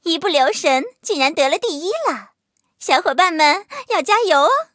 M2中坦MVP语音.OGG